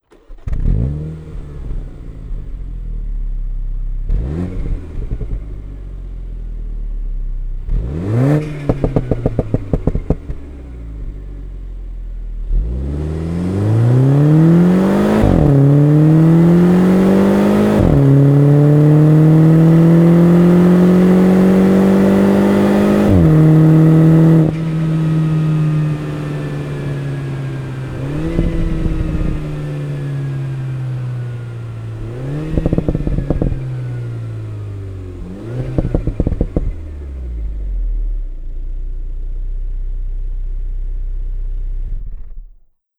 ノーマルマフラー